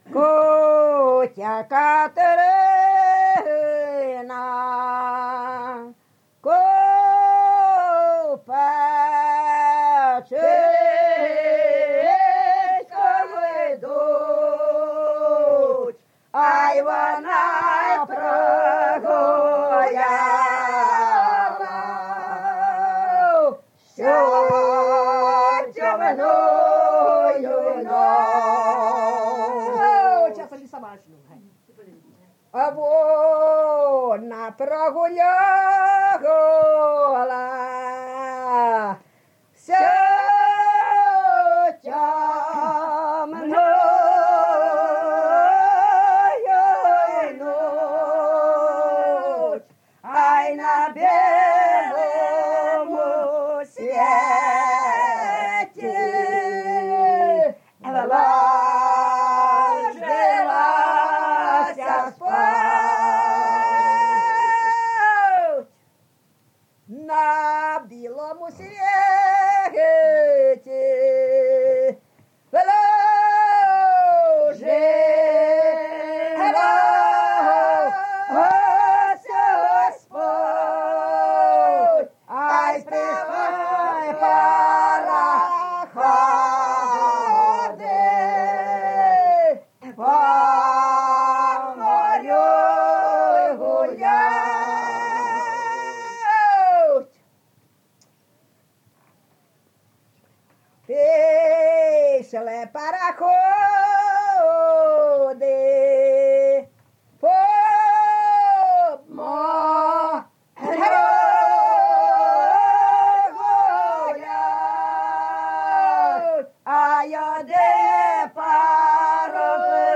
ЖанрПісні з особистого та родинного життя
Місце записус. Куземин, Охтирський район, Сумська обл., Україна, Слобожанщина